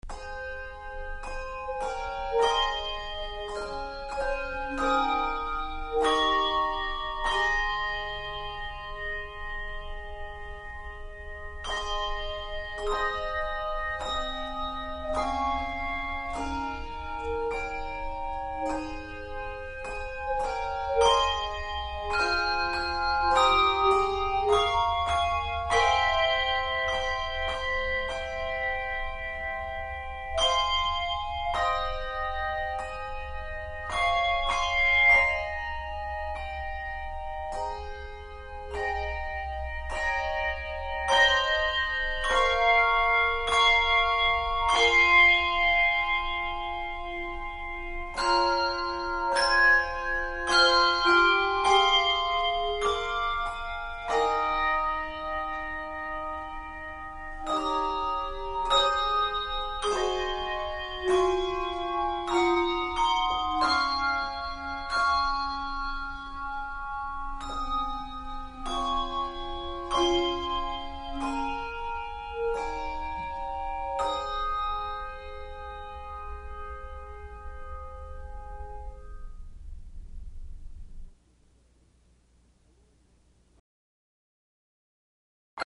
Handbell Quartet
Genre Sacred
No. Octaves 3 Octaves